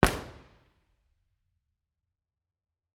IR_EigenmikeHHB1_processed_Bformat.wav